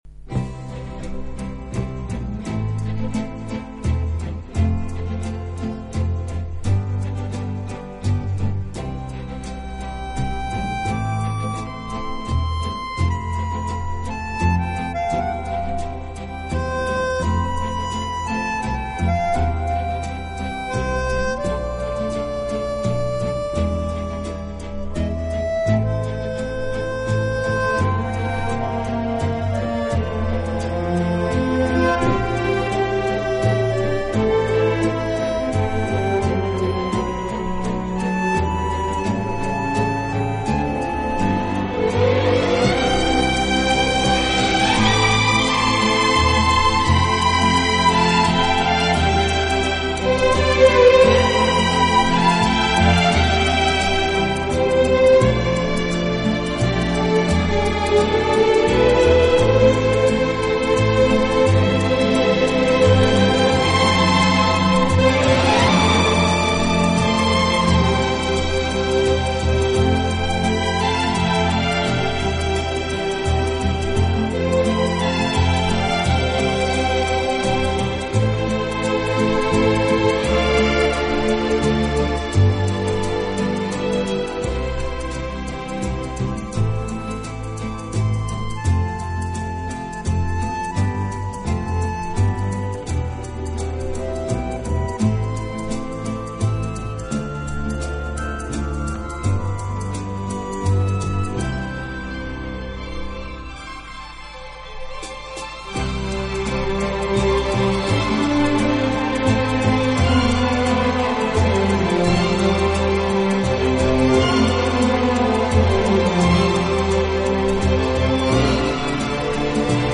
【顶级轻音乐】